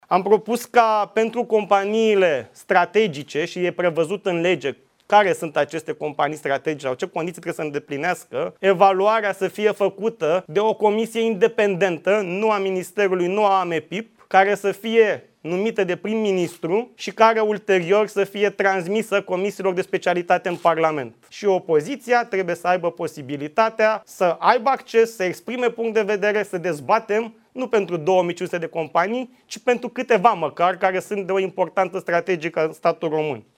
Ministrul Economiei, Radu Miruță: Comisia independentă să fie numită de prim-ministru și care ulterior să fie transmisă comisiilor de specialitate din Parlament